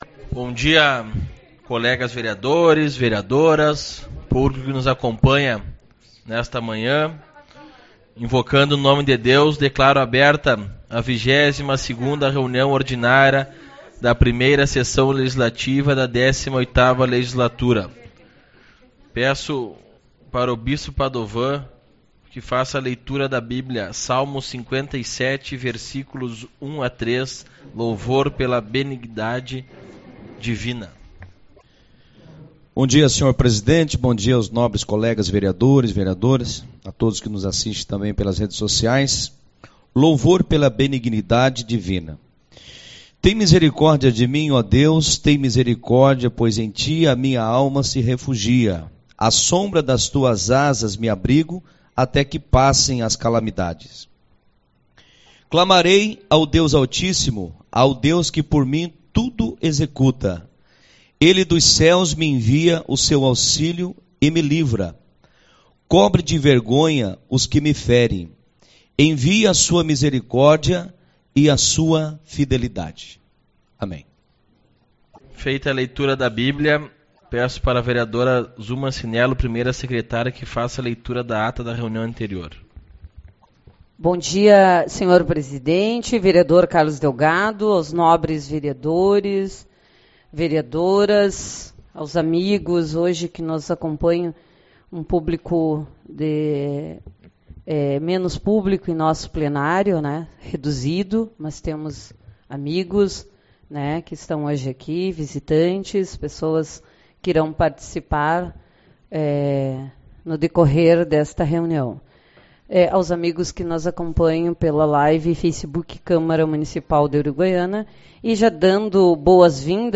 15/04 - Reunião Ordinária